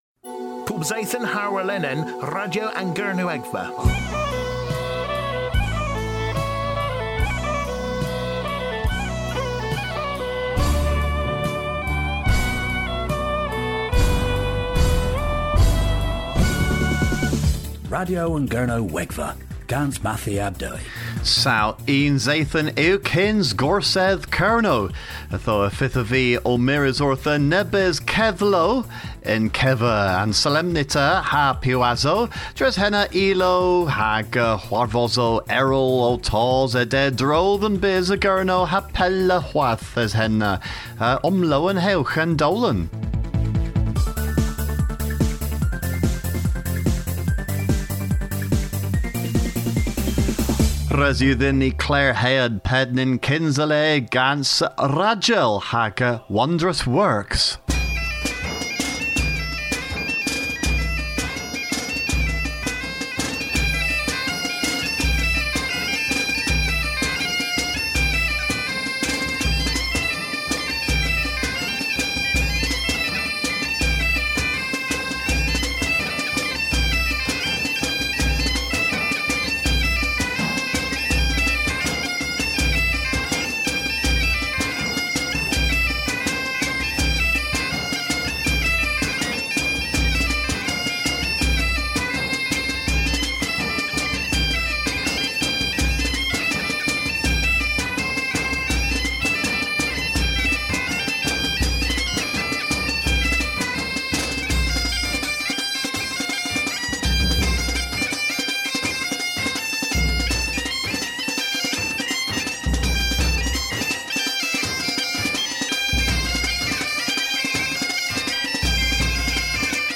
Ni a woslow orth ilow gwlaskarek ha klappya gans dew vardh meur kyns.